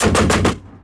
CV90 firing and modified
These are 40mm guns so suitable for a heavy IFV unit.